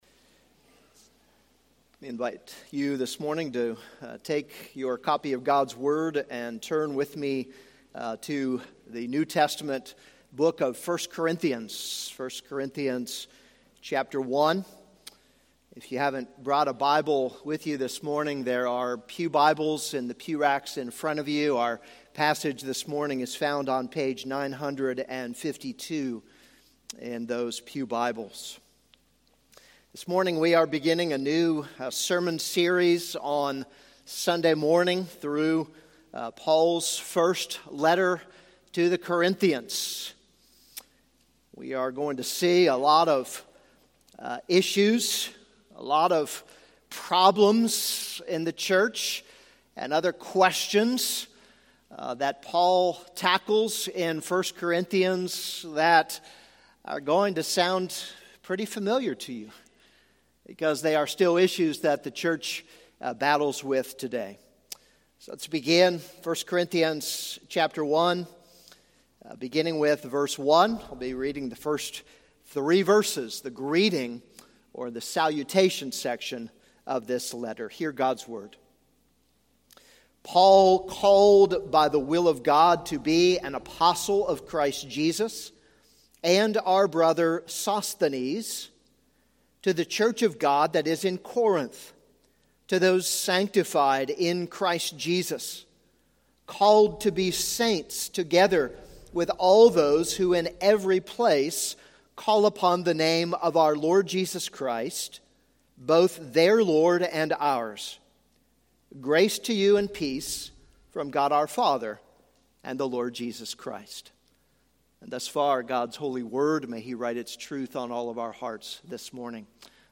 This is a sermon on 1 Corinthians 1:1-3.